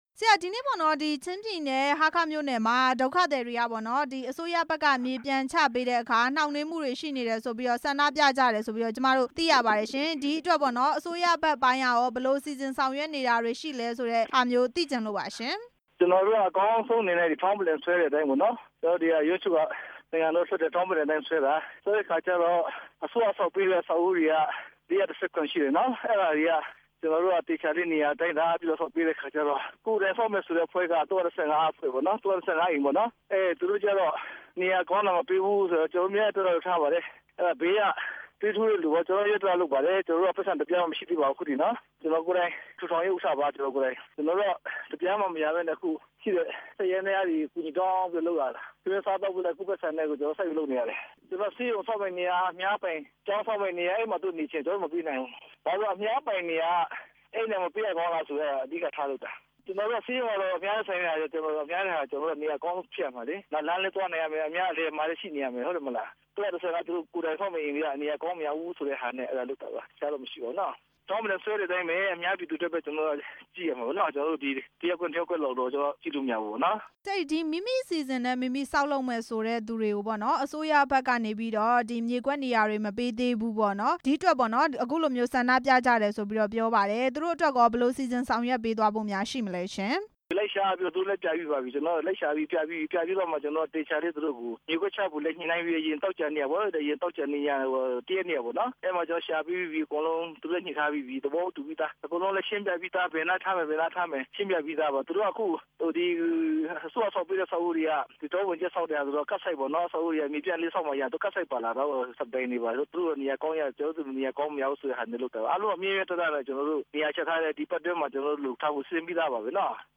ဟားခါးမြို့မှာ ဒုက္ခသည်ပြည် သူ ထောင်နဲ့ချီ ဆန္ဒပြတဲ့ အကြောင်း မေးမြန်းချက်